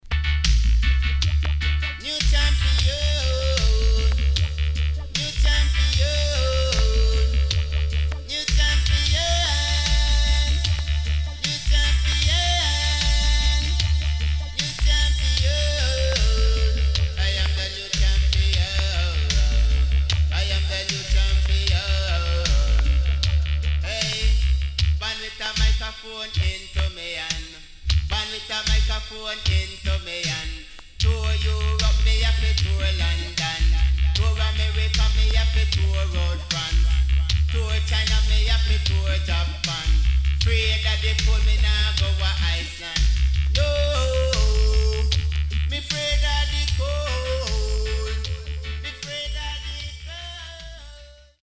HOME > Back Order [DANCEHALL DISCO45]
SIDE A:少しプチノイズ入りますが良好です。